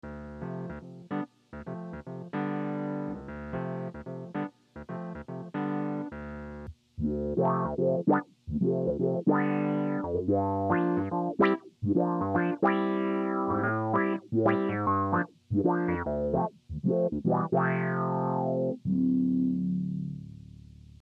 envelope follower wurlitzer.mp3 (effect out, effect in)
The result is a great auto-wah sound with tons of character and responsiveness.
envelope follower synth wurlitzer.mp3